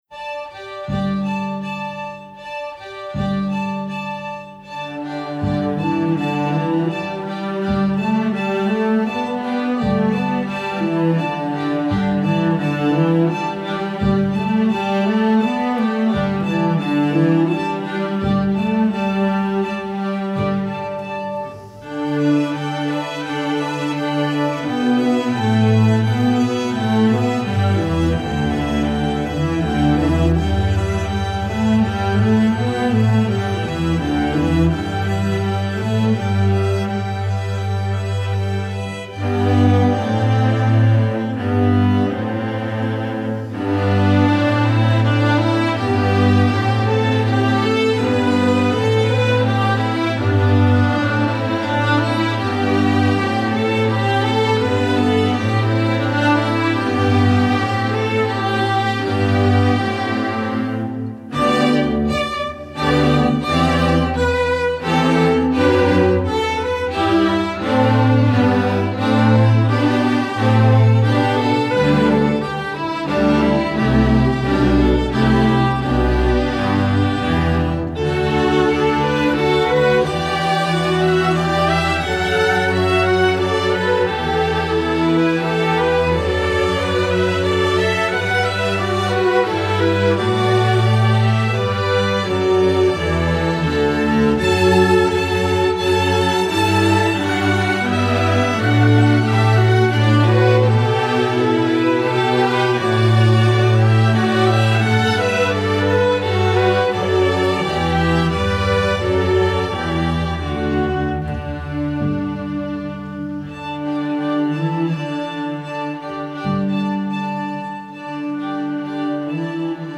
Composer: Korean Folk Song
Voicing: String Orchestra